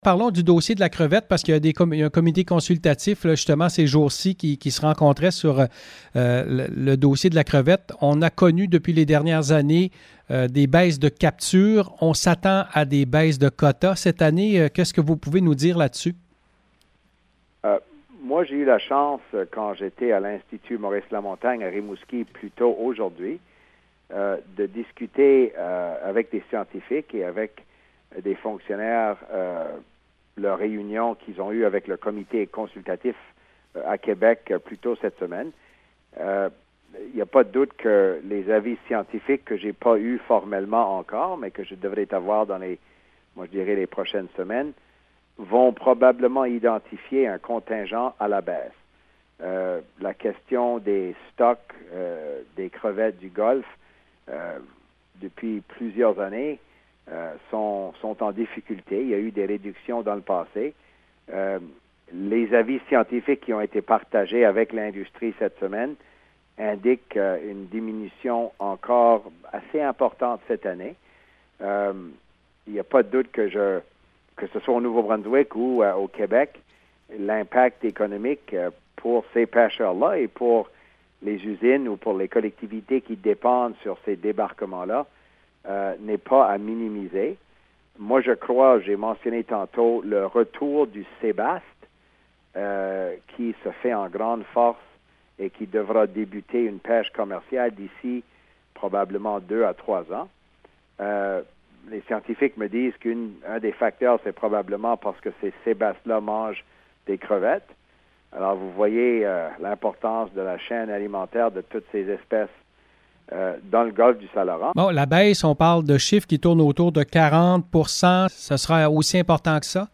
Entrevue avec Dominic LeBlanc: